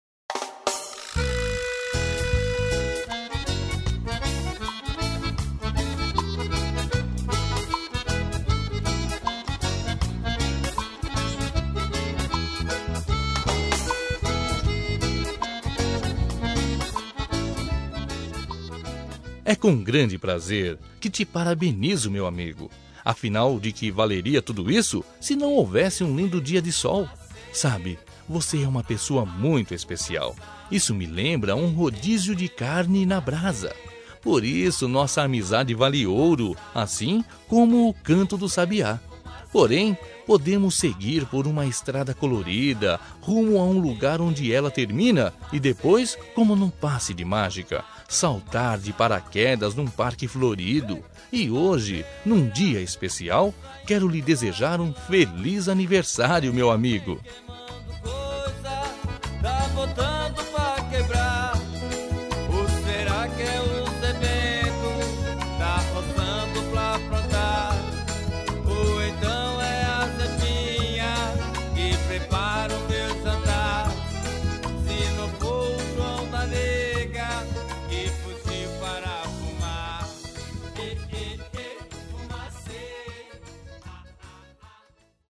Aniversário de Humor – Voz Masculina- Cód: 200206